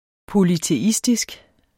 Udtale [ polyteˈisdisg ]